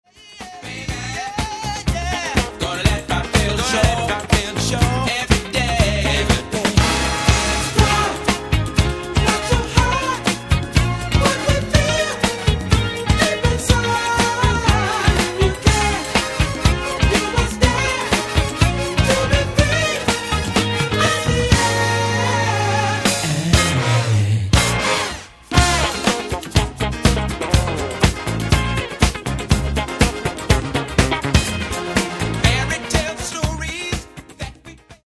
Genere:   Funky | Soul